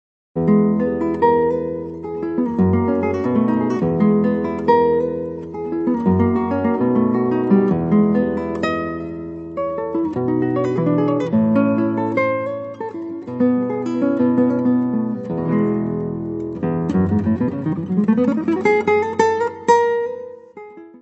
guitarra.
Área:  Música Clássica